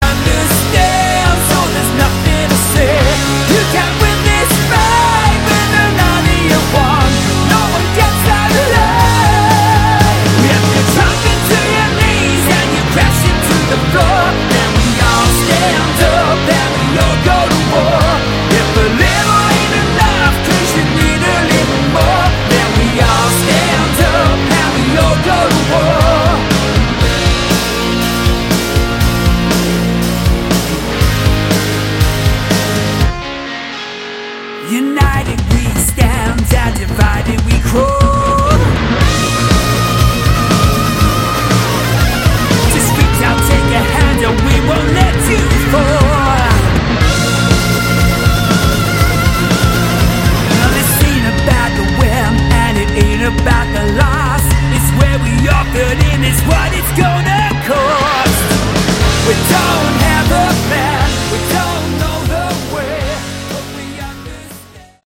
Category: Melodic Rock
keyboards
lead guitar
drums
guitar, backing vocals
lead & backing vocals, acoustic guitar
bass